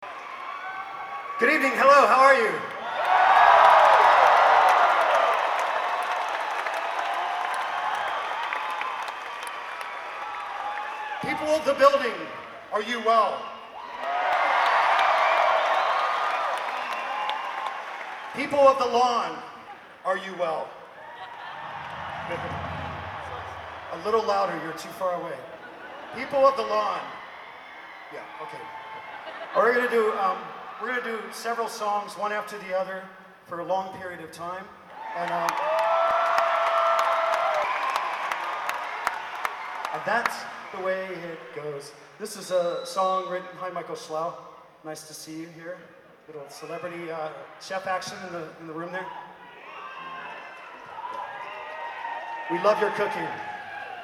Live at at the Comcast Center
in Mansfield, MA
banter 1
Live sets recorded with a Sony ECM-719 mic and a Sony MZ-RH10 minidisc (except for track #31 above), converted to .wav and then edited to 192kbps Mp3s.